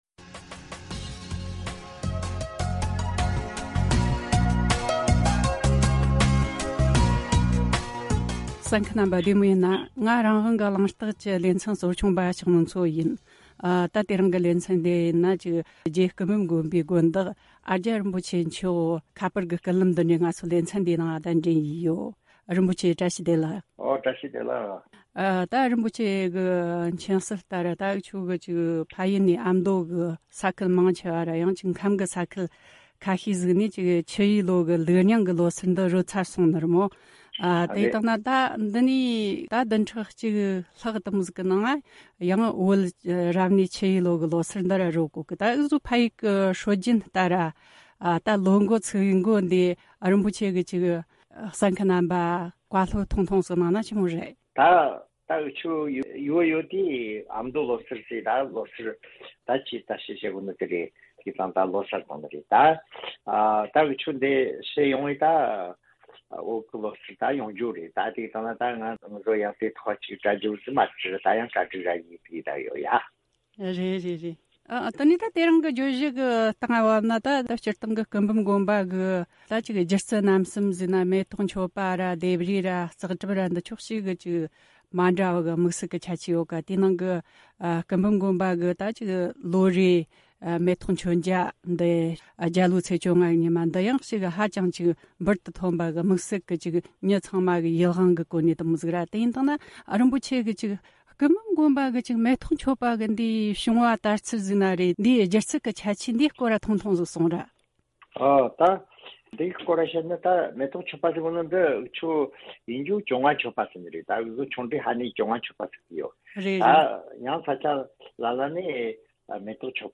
གླེང་མོལ་ཞུས་བར་གསན་རོགས་གནོངས།